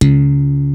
SLAP 2.wav